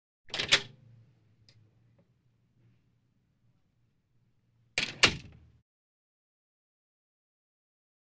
HalfGlassWoodDoor3 PE181401
Half Glass / Wood Door 3; Open With Latch And Long Time To Close.